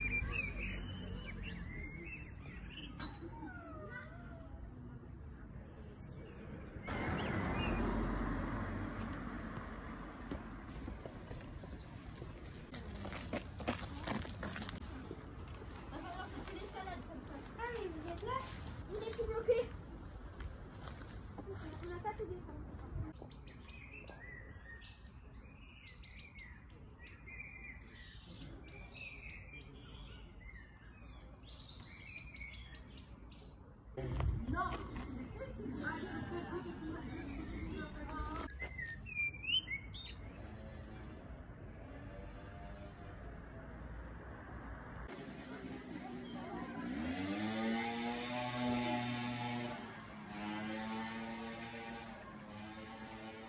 Son Parc de la Colonne.m4a
son-parc-de-la-colonne.m4a